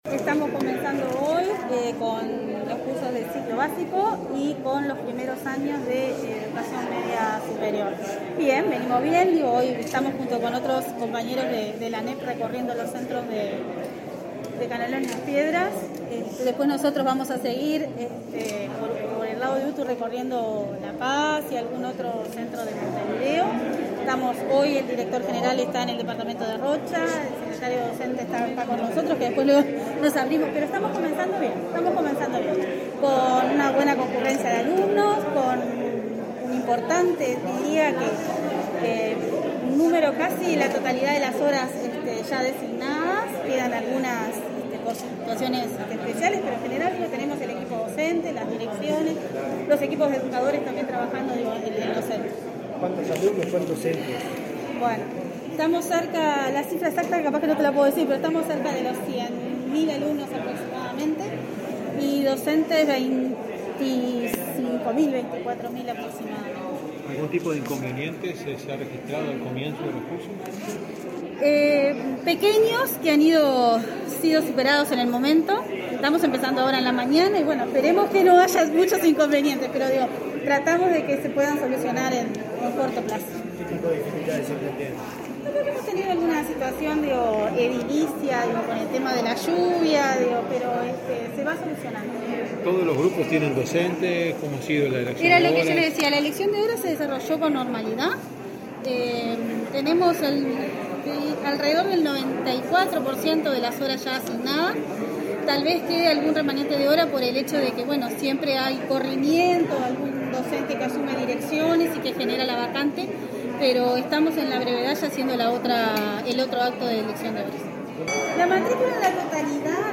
Declaraciones a la prensa de la subdirectora de UTU, Laura Otamendi
Declaraciones a la prensa de la subdirectora de UTU, Laura Otamendi 07/03/2022 Compartir Facebook X Copiar enlace WhatsApp LinkedIn La subdirectora de UTU, Laura Otamendi, dialogó con la prensa, luego de realizar una recorrida por diversos centros educativos de Canelones, junto al presidente del Consejo Directivo Central (Codicen) de la ANEP, Robert Silva.